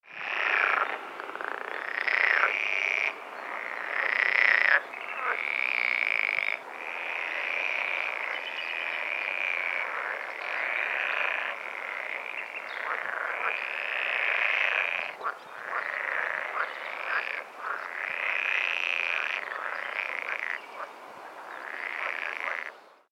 Rufe Kleiner Wasserfrosch: schnarrende Paarungsrufe, quakende Revierrufe Ihr Browser kann dieses Tondokument nicht wiedergeben.